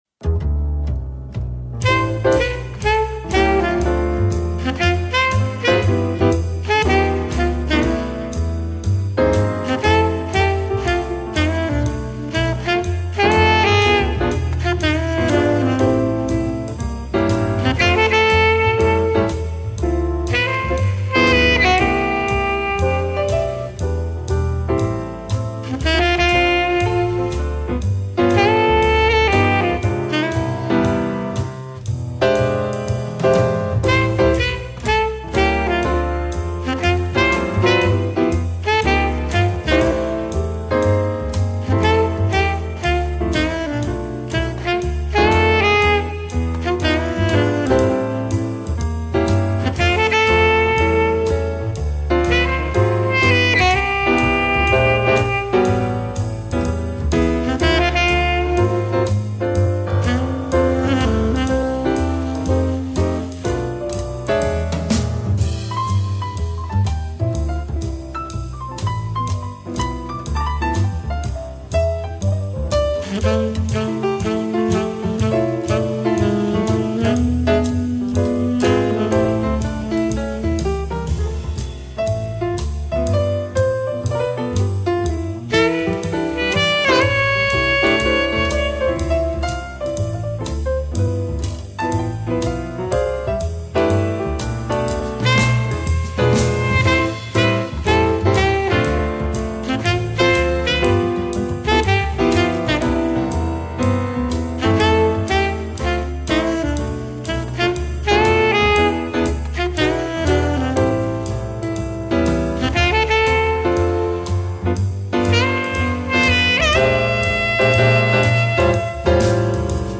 SAX - Latino